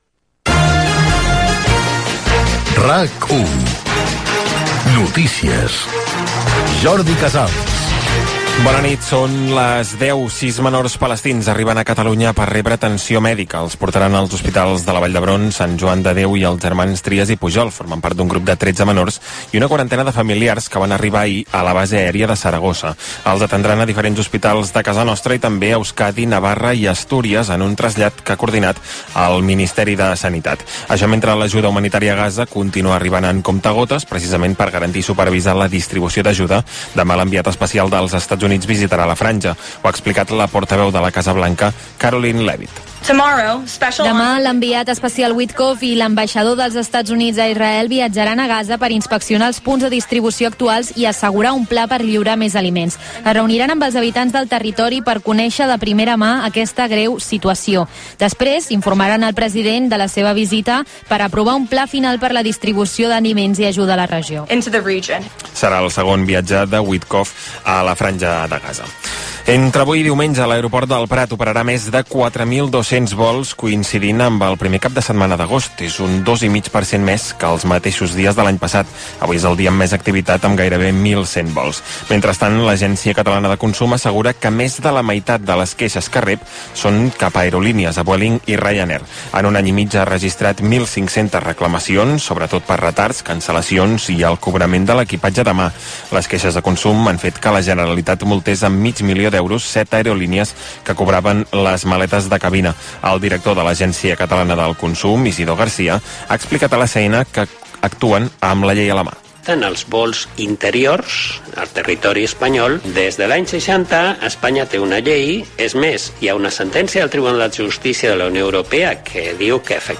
Careta del programa, guerra de Palestina i situació a la franja de Gaza, aeroport del Prat, detenció al barri d'Horta, esports, careta de sortida, indicatiu estiuenc de l'emissora
Informatiu